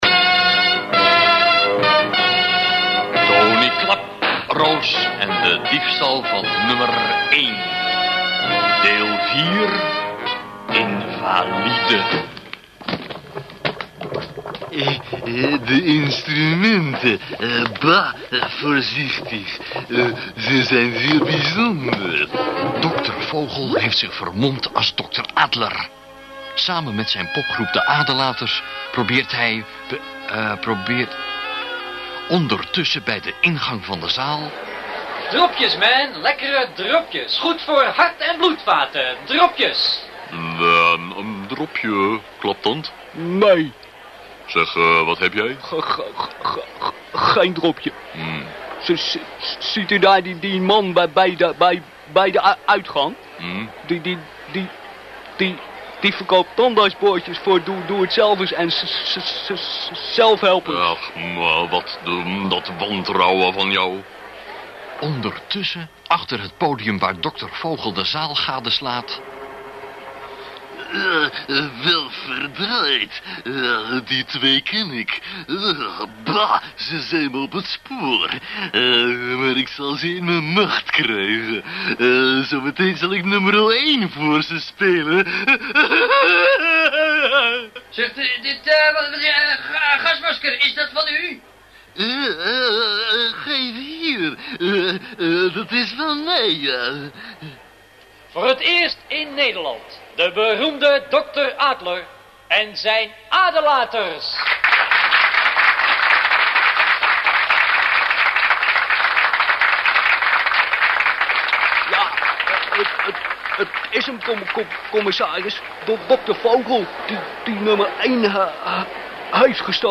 Ik vond in een oude doos een paar tapes met mono opnames van crappy kwaliteit.